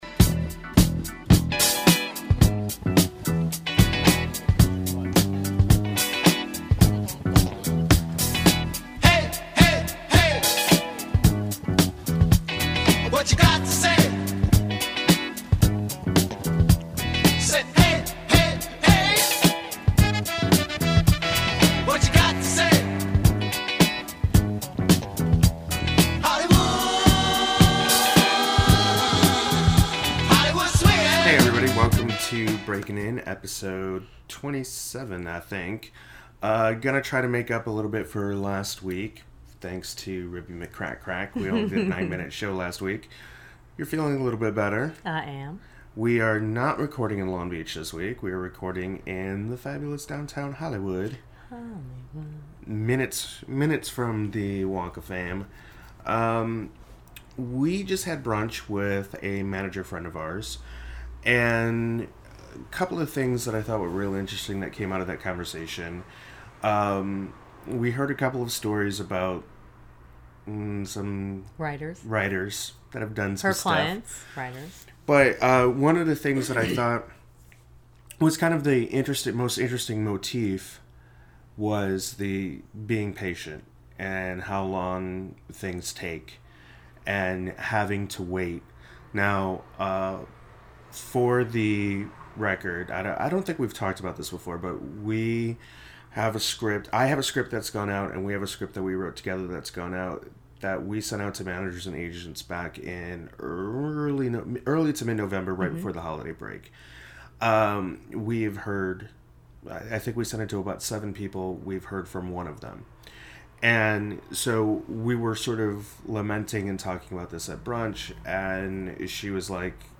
This week, we record from the fabulous heart of Hollywood where we discuss overwhelming personalities and colorful characters. We also chit chat a little about the brunch meeting we had right before we recorded.